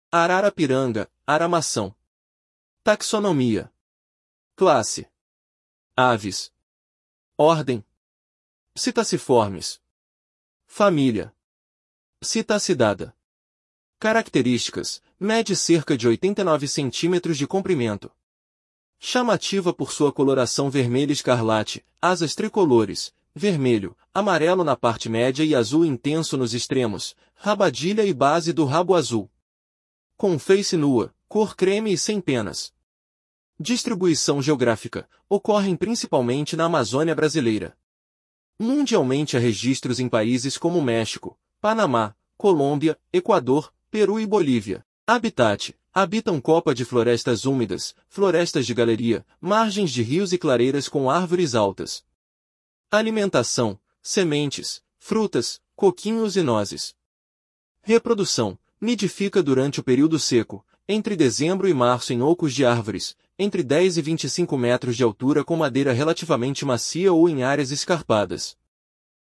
Arara-piranga (Ara macao)